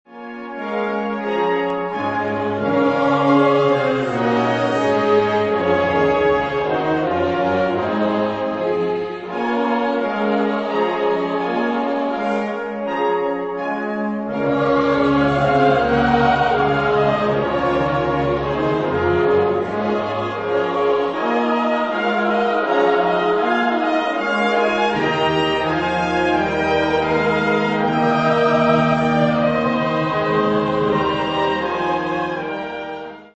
Genre-Style-Forme : Sacré ; Anthem ; Psaume
Type de choeur : SATB  (4 voix mixtes )
Instrumentation : Clavier  (1 partie(s) instrumentale(s))
Instruments : Orgue (1)